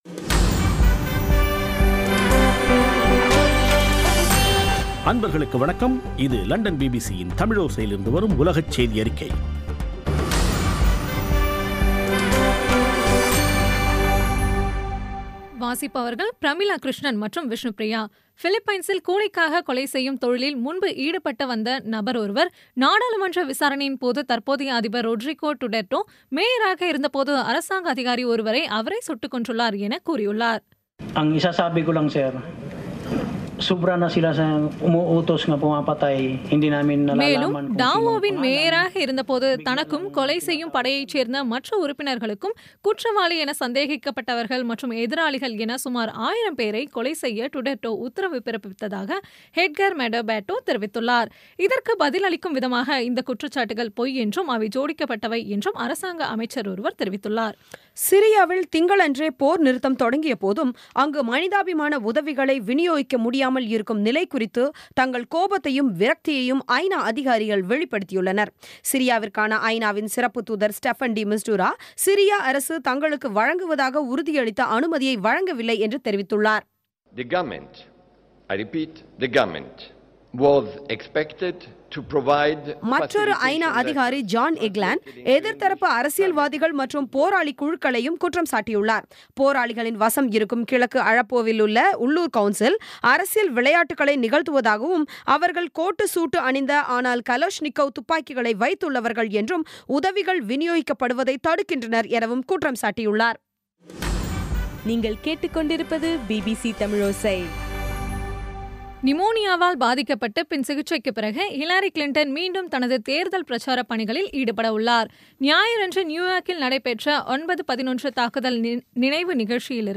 பி பி சி தமிழோசை செய்தியறிக்கை (15/09/2016)